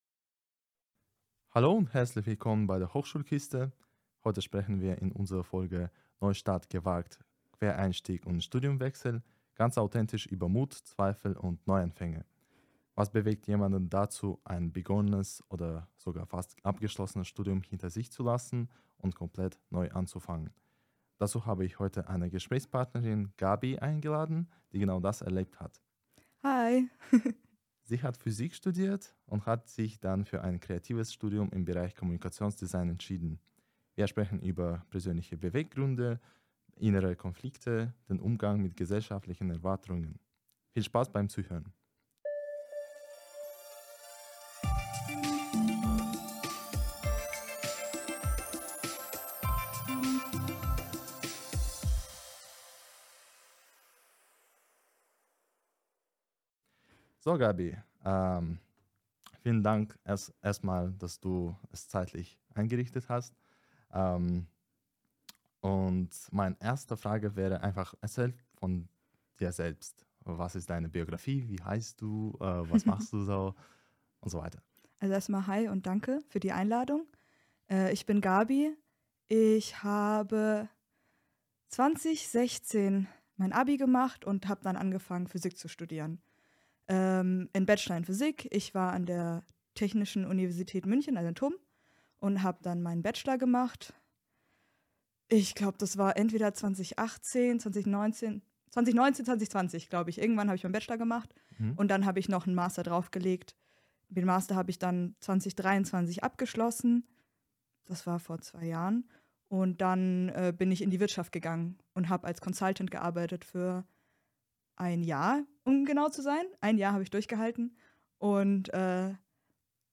In dieser Folge geht es um Studienwechsel und Quereinstieg – wenn man merkt, dass der erste Weg doch nicht der richtige war. Wir sprechen mit einer Studentin, die den Sprung gewagt hat: vom Physikstudium hin zu kreativem Mediendesign.
Ein Gespräch über das Umdenken, Umwege und den Mut zum Neubeginn.